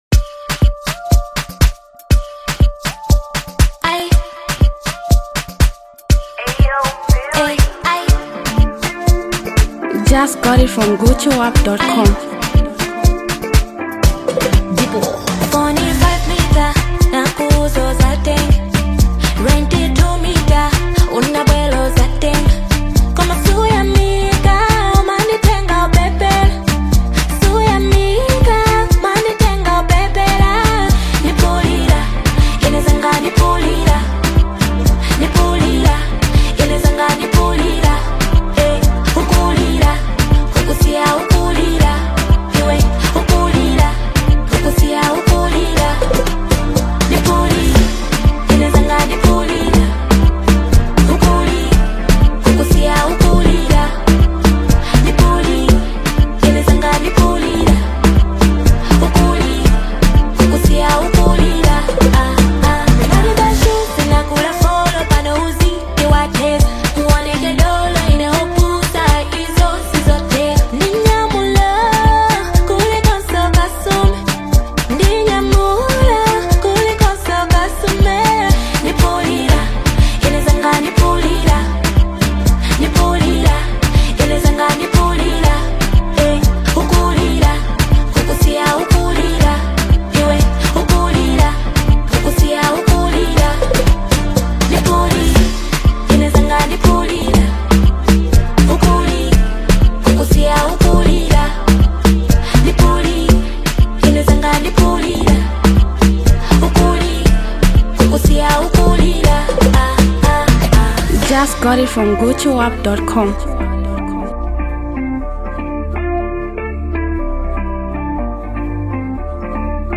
Malawian renowned female singer and songwriter